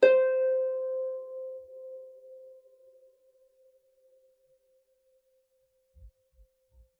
KSHarp_C5_mf.wav